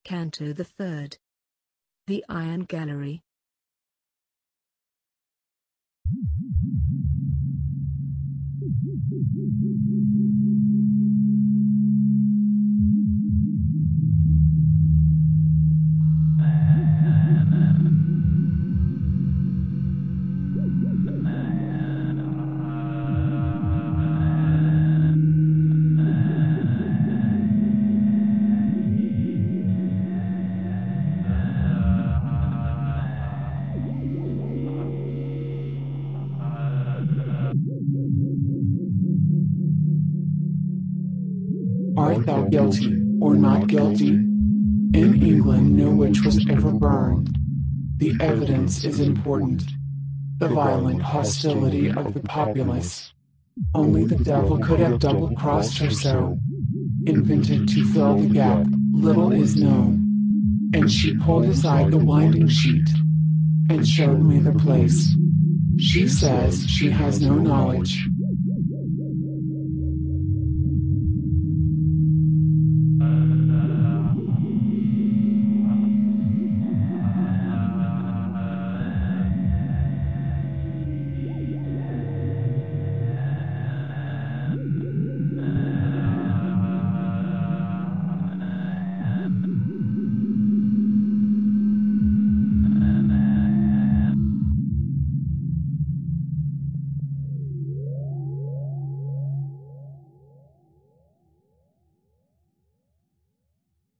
The voices reciting the texts are synthesized.